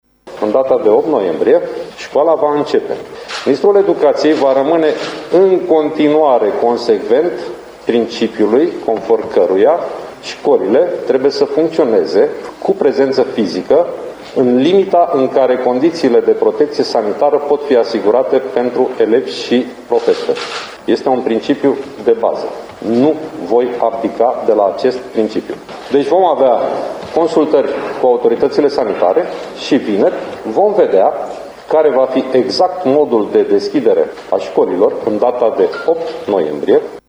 Câmpeanu a declarat că vor avea loc consultări cu autoritățile sanitare, iar vineri va fi anunțat modul în care vor învăța elevii: